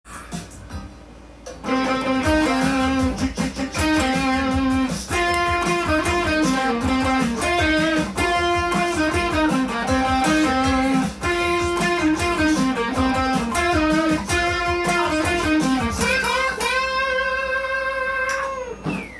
使用されているスケールはCmペンタトニックです。
ブルージーな感じが逆に面白さを醸し出しています。
リズムは、洋画の映画導入歌なので裏のリズムが多くなっています。